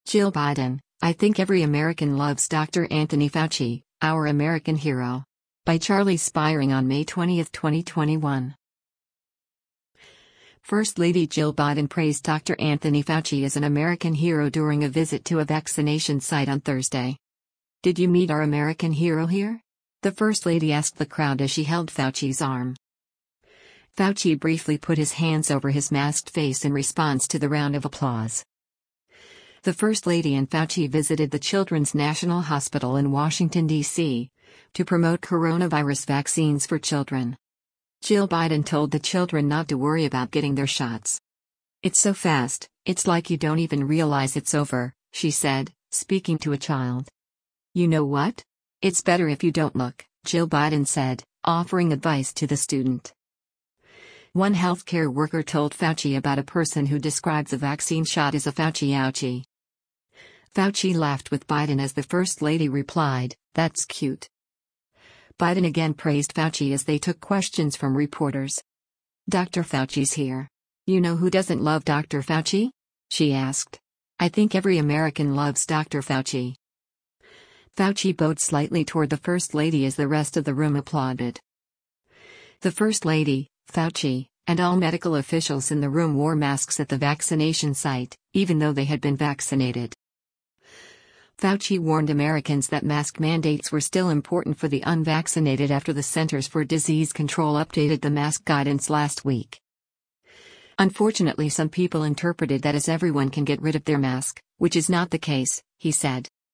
First lady Jill Biden praised Dr. Anthony Fauci as an American hero during a visit to a vaccination site on Thursday.
Fauci briefly put his hands over his masked face in response to the round of applause.
Fauci bowed slightly toward the first lady as the rest of the room applauded.